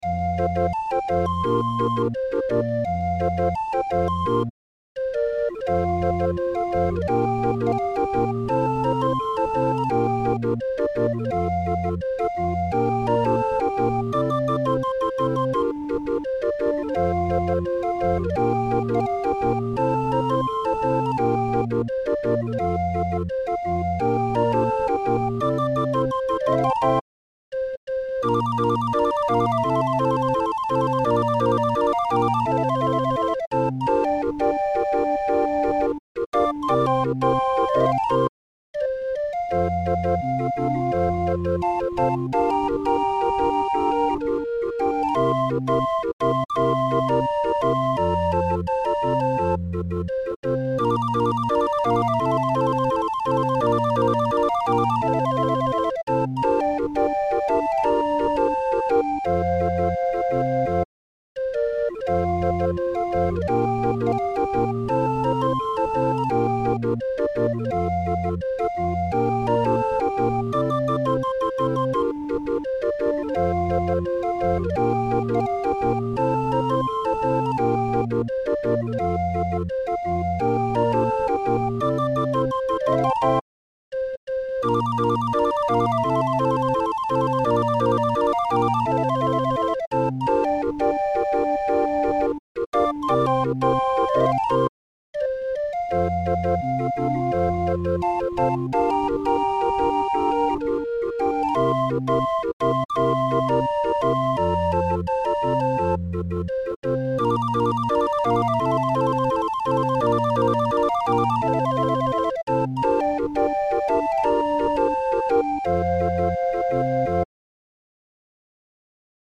Musikrolle 20-er